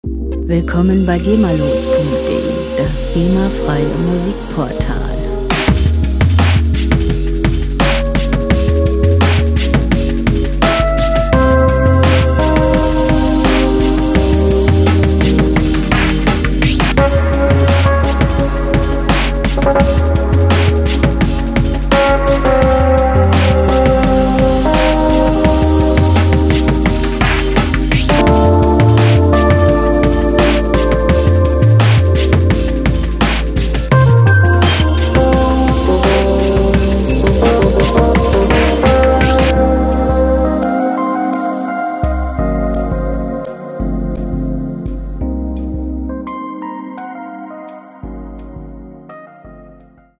• Drum and Bass